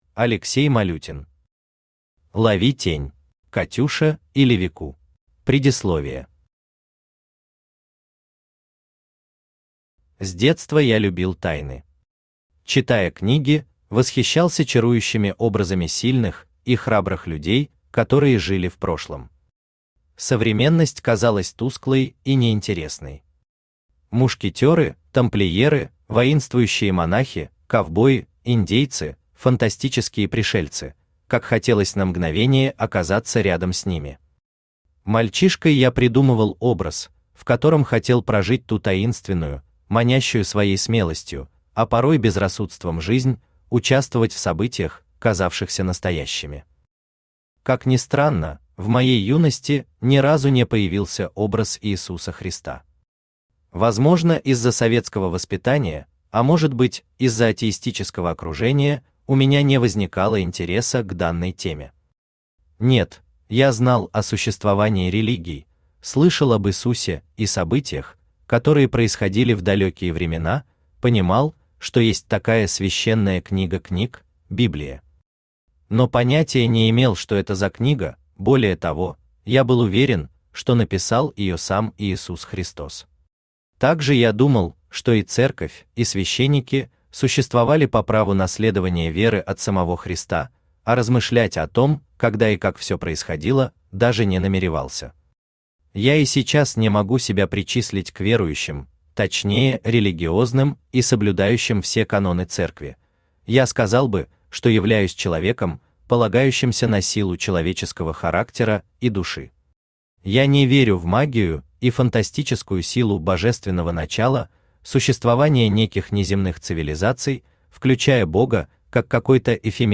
Аудиокнига Лови тень | Библиотека аудиокниг
Aудиокнига Лови тень Автор Алексей Павлович Малютин Читает аудиокнигу Авточтец ЛитРес.